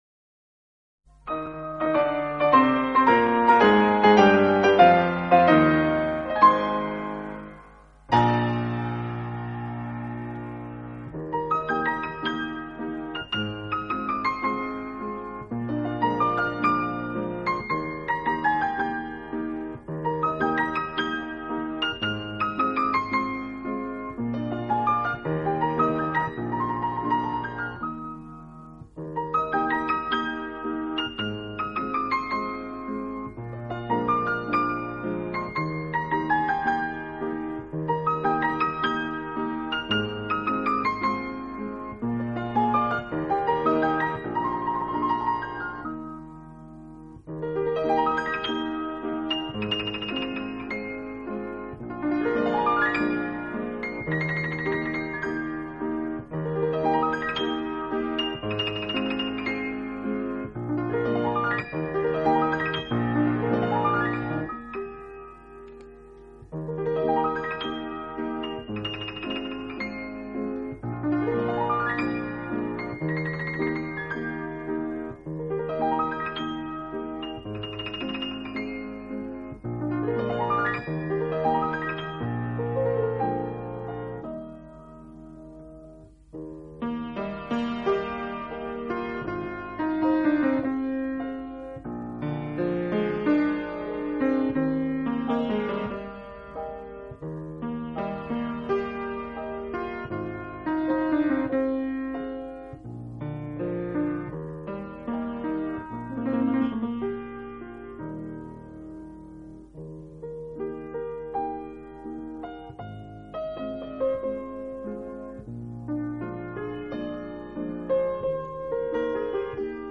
【浪漫钢琴】
钢琴演奏版，更能烘托出复古情怀，欧美钢琴大师深具质感的演奏功力，弹指
本套CD全部钢琴演奏，